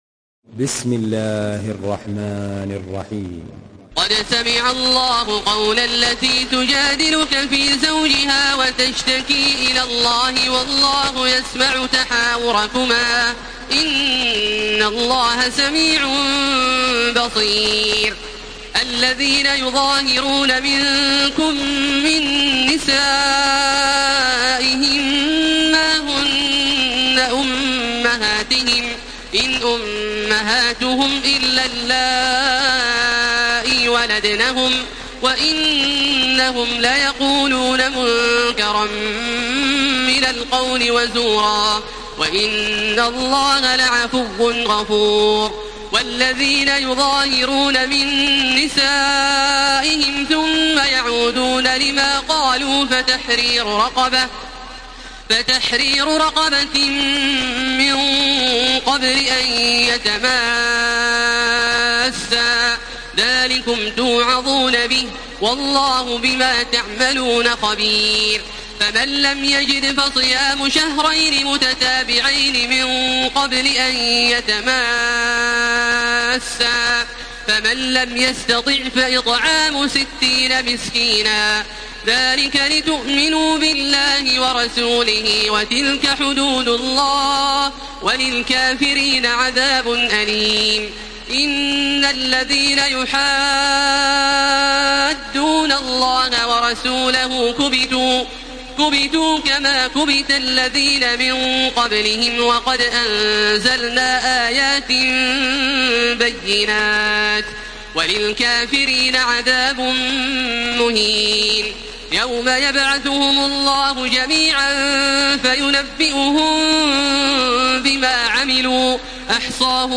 Surah Al-Mujadilah MP3 in the Voice of Makkah Taraweeh 1433 in Hafs Narration
Murattal Hafs An Asim